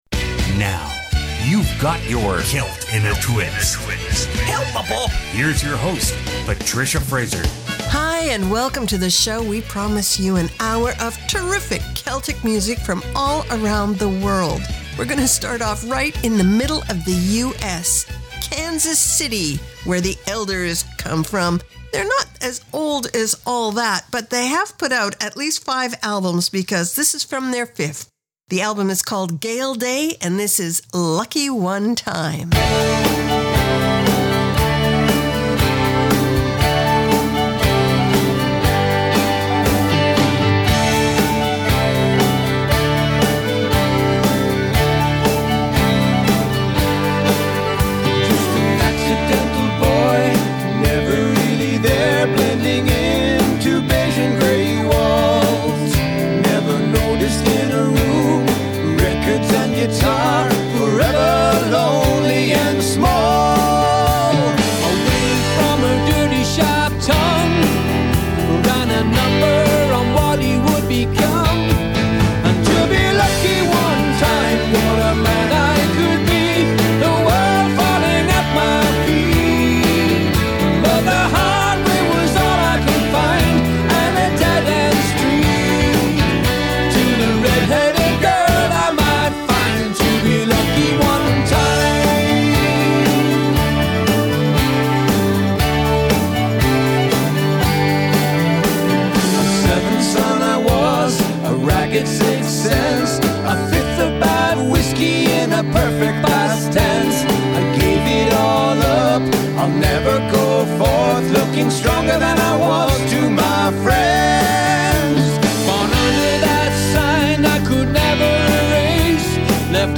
Canada's Contemporary Celtic Radio Hour
Try Belgian Celtic,Galician Celtic, Danish Celtic & Celtic fr. around the corner this hour!